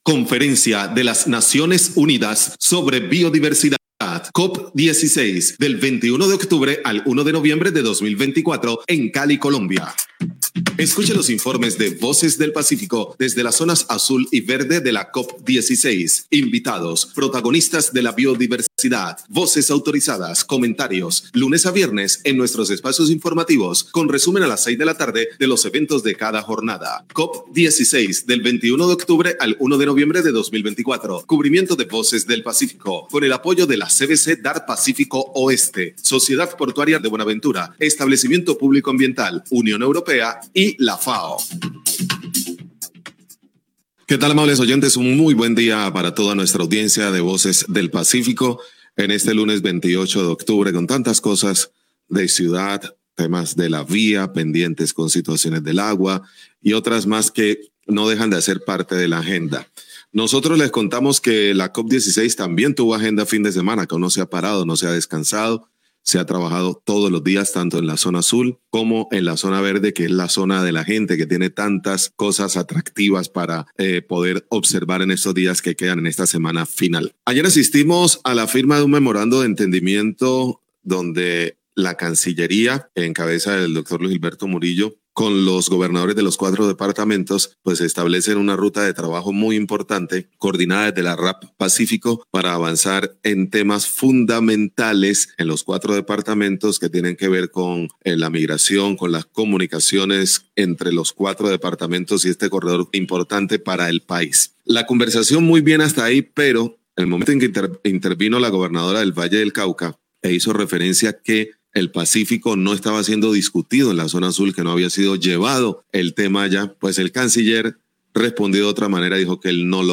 Informe COPA16
Radio